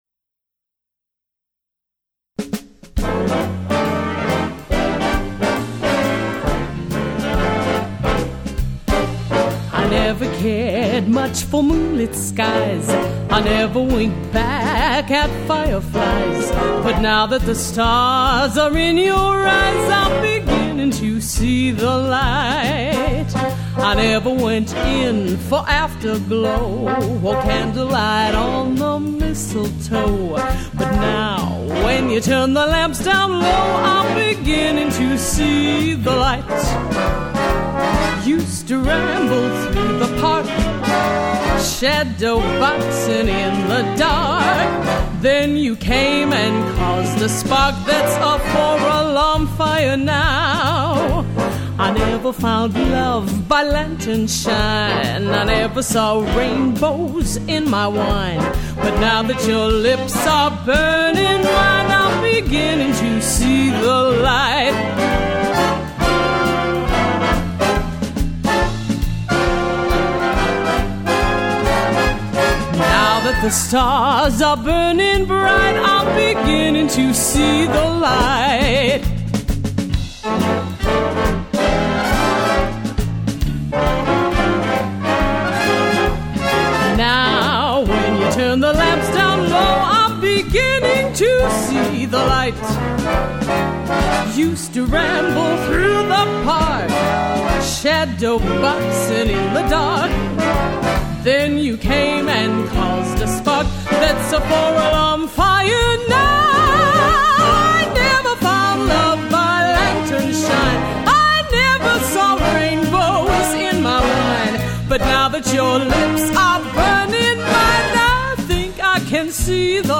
Possessing a rare versatility in timbre and range
brings warmth, finesse, and yet, excitement and strength